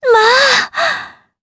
Laudia-Vox_Happy4_jp.wav